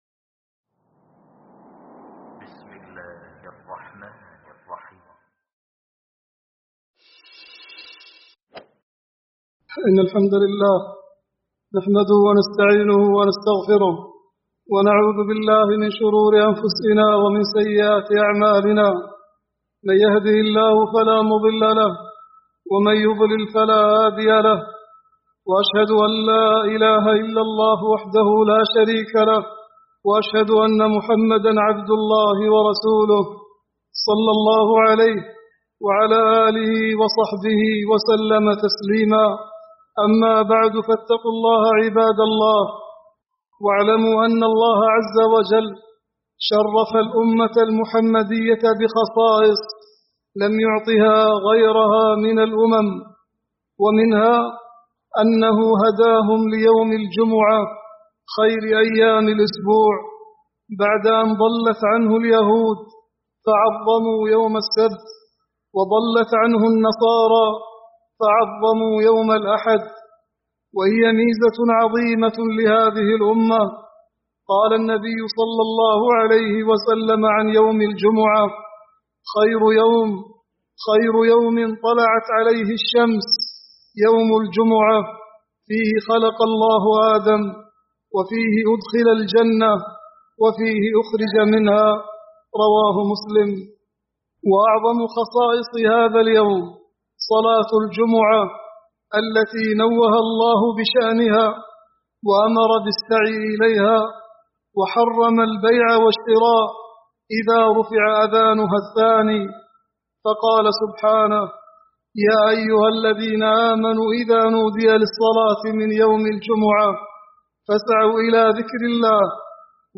خطبة